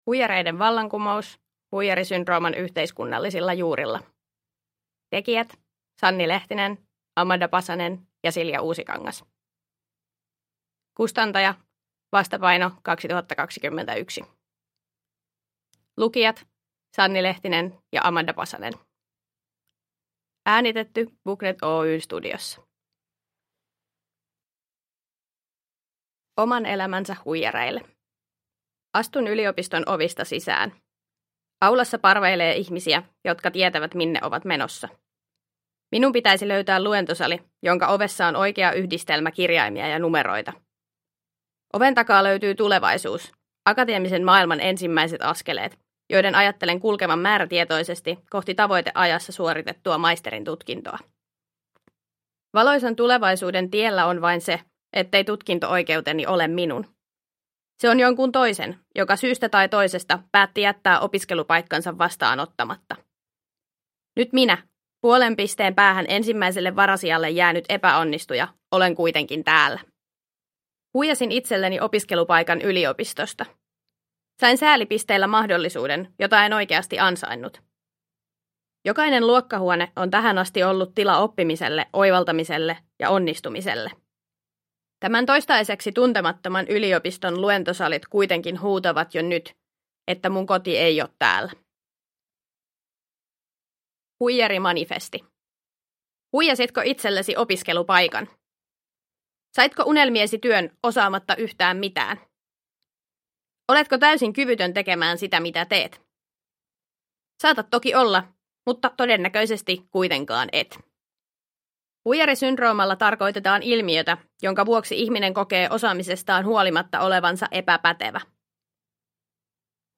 Huijareiden vallankumous – Ljudbok – Laddas ner